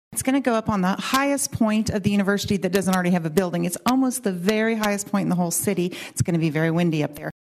In her commentary, she is speaking recently to a legislative committee studying the capital needs of the state’s regional colleges and universities.